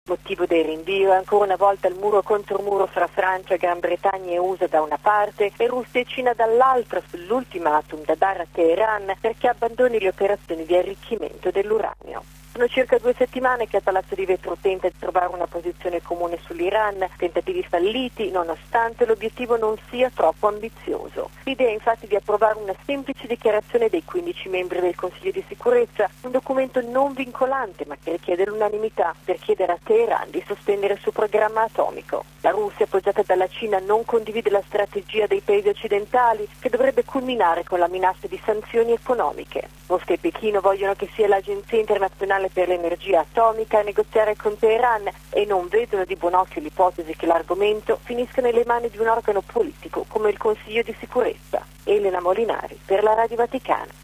(22 marzo 2006 - RV) Sulla questione nucleare iraniana ancora un nulla di fatto all’Onu. Ieri pomeriggio, i contrasti tra i 15 membri del Consiglio di Sicurezza hanno fatto optare per un rinvio a data da destinarsi di ogni decisione sanzionatoria nei confronti di Teheran. Il servizio, da New York,